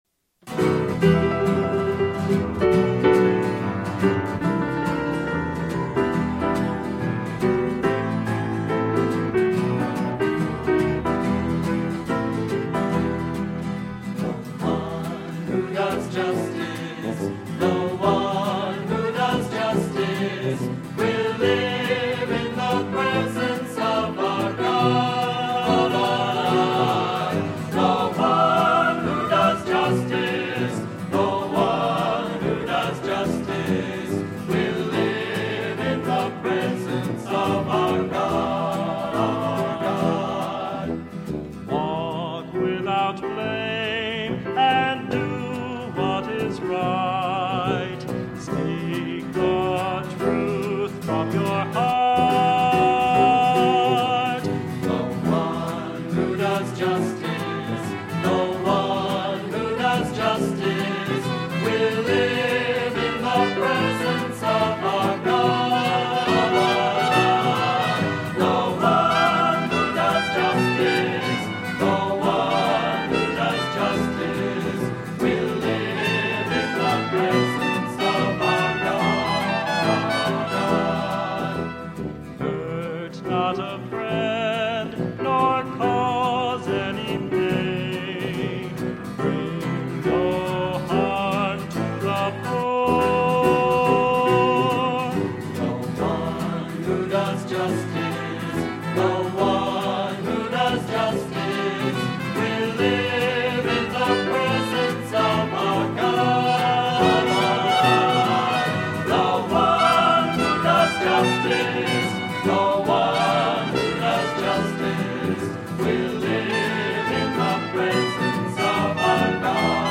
Voicing: SATB, cantor, assembly